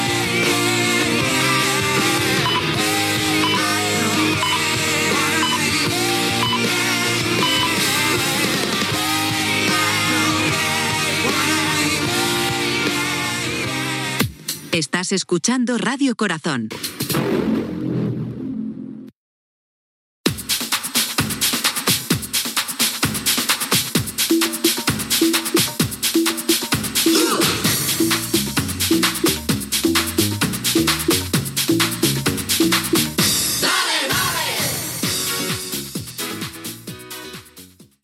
Tema musical, identificació de la ràdio i tema musical Gènere radiofònic Musical